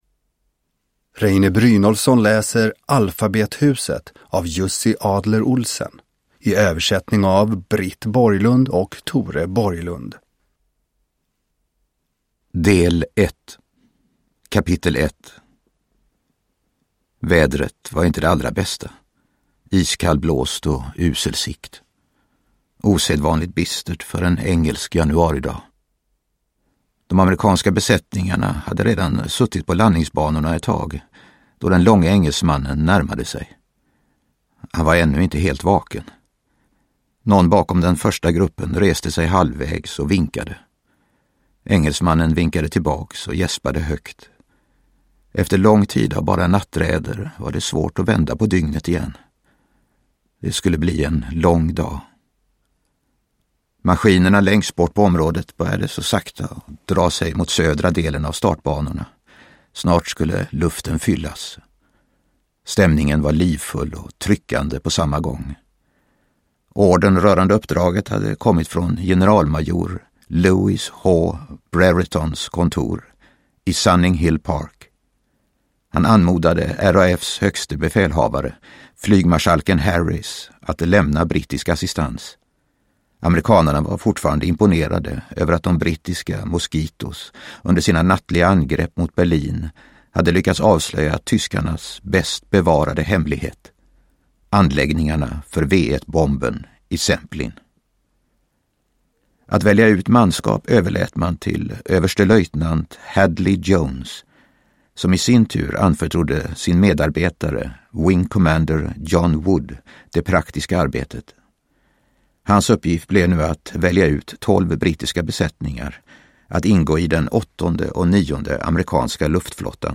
Alfabethuset – Ljudbok – Laddas ner
Uppläsare: Reine Brynolfsson